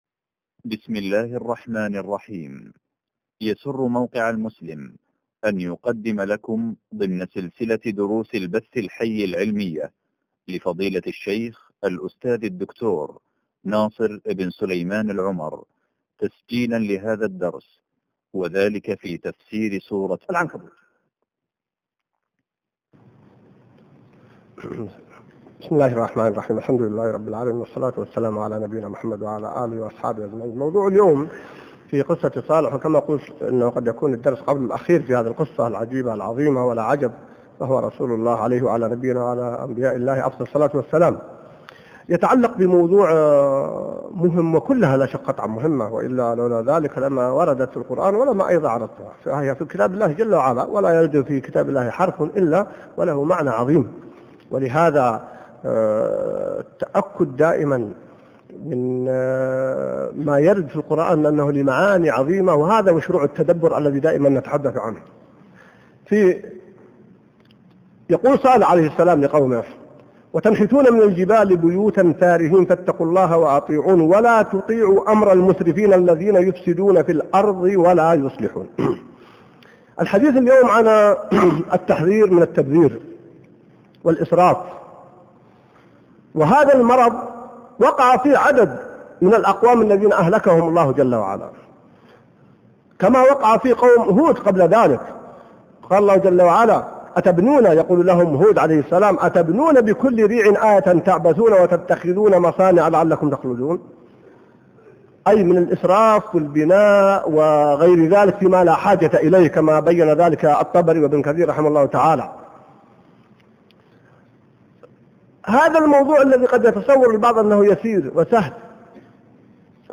الدرس 51 من تفسير سورة العنكبوت | موقع المسلم